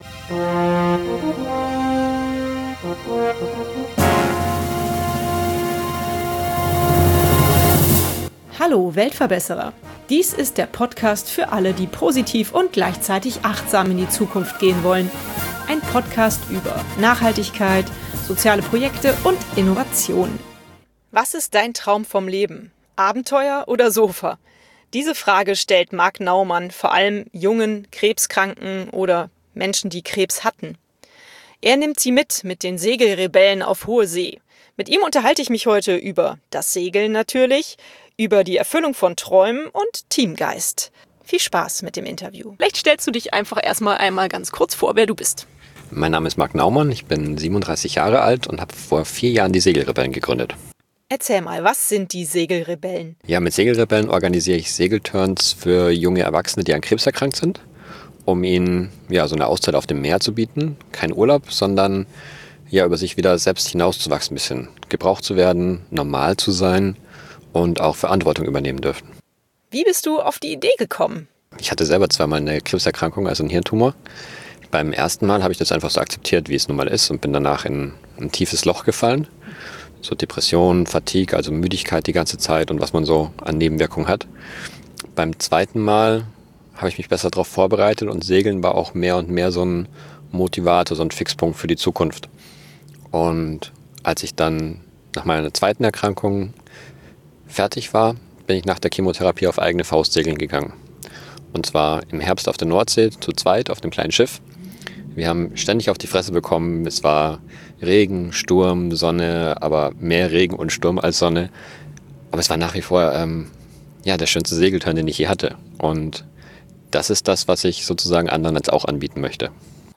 Wir sitzen vor einer großen Autowaschanlage in Köln und unterhalten uns über das Segeln, über Revolution, Mut, Teamgeist und das Erfüllen von Lebensträumen.